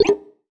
Simple Cute Alert 30.wav